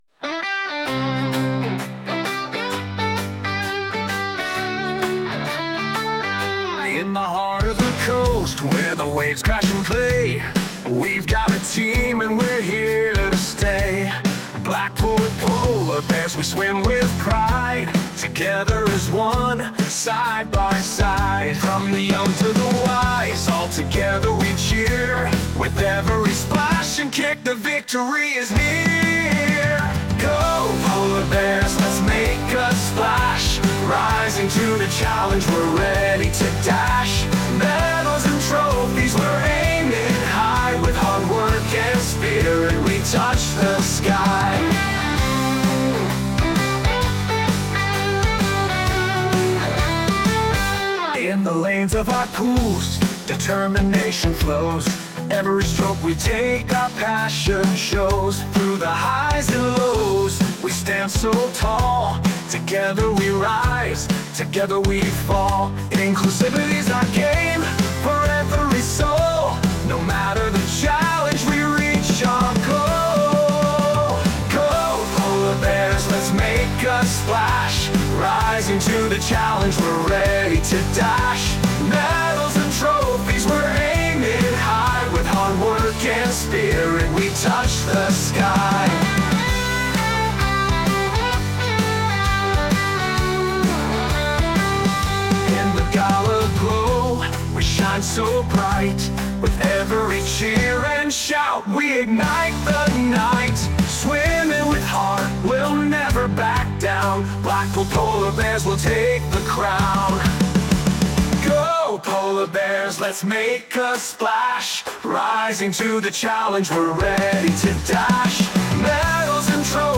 Or download for use at swimming events: Download Blackpool Polar Bears Anthem